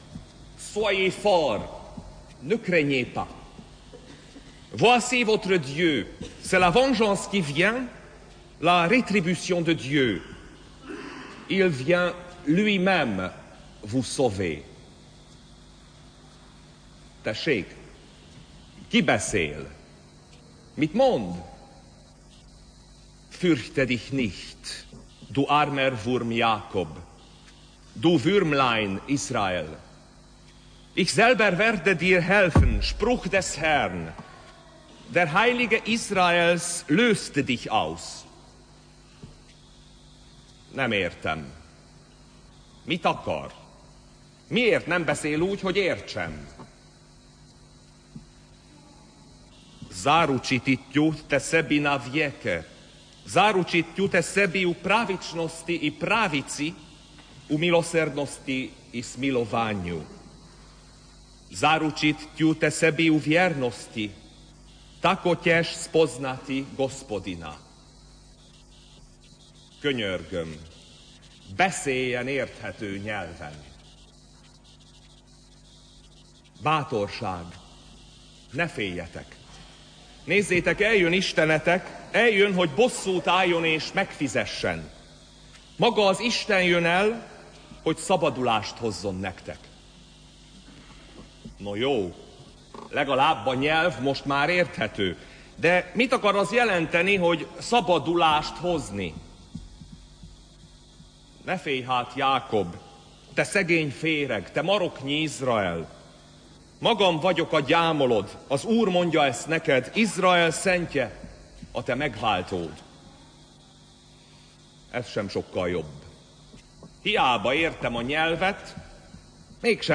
Urunk születése – Ünnepi mise